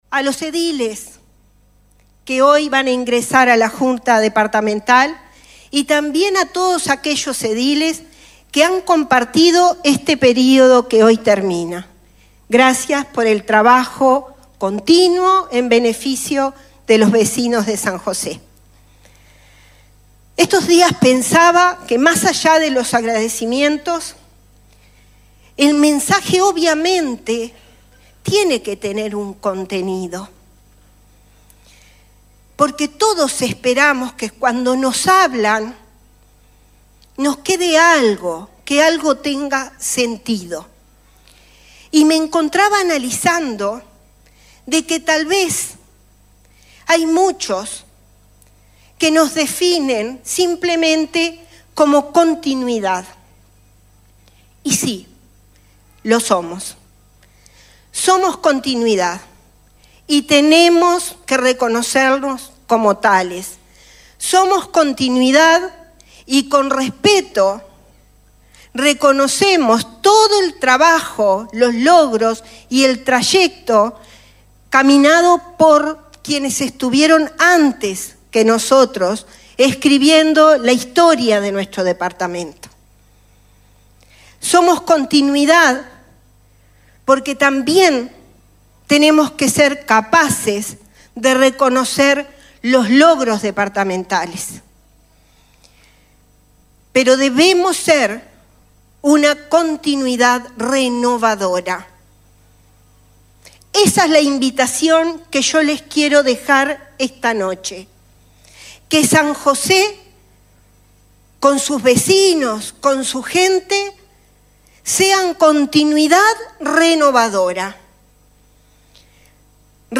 Con la presencia de autoridades nacionales y departamentales se llevó a cabo ayer la ceremonia de traspaso de mando en dónde Ana Bentaberri asumió nuevamente como Intendenta del Departamento. En su alocución hizo referencia a la primer medida que tomará y se trata de la conformación de la Mesa de Concertación de Políticas Públicas, con la integración de los Partidos Políticos que quieran participar de ella.
Repasamos parte de su discurso: